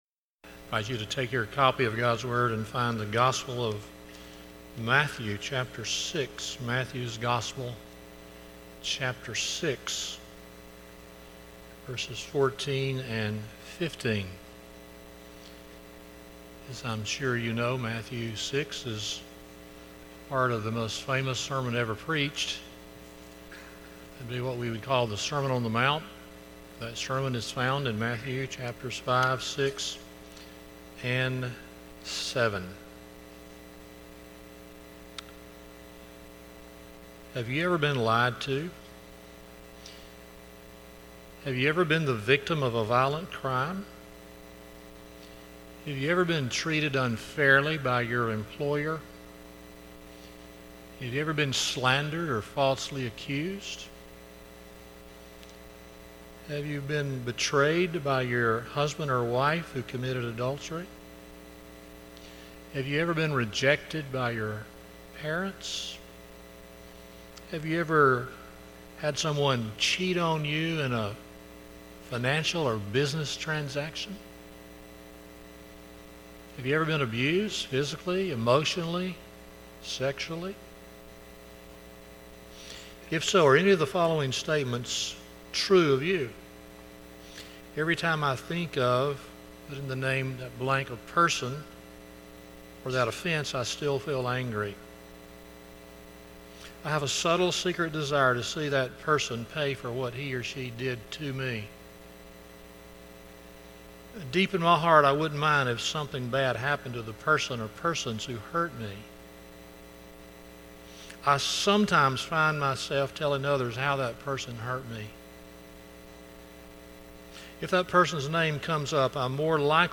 Matthew 6:14-15 Service Type: Sunday Evening 1.